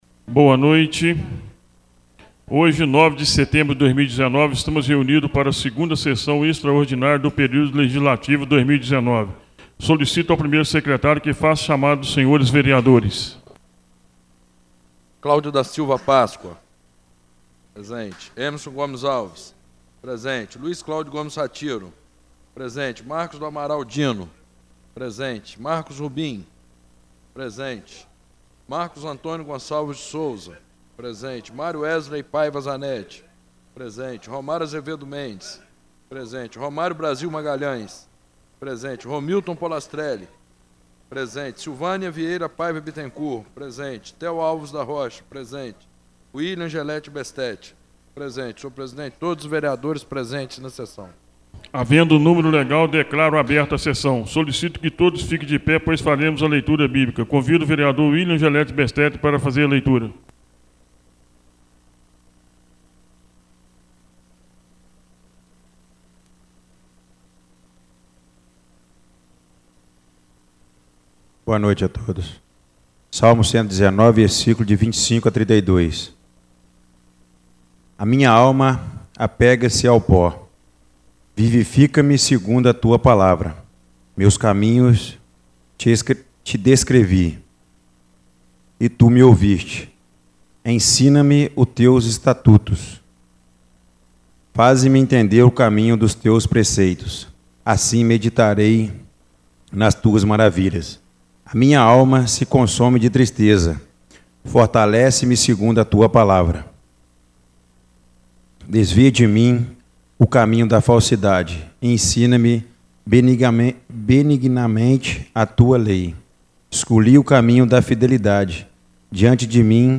Tipo de Sessão: Extraordinária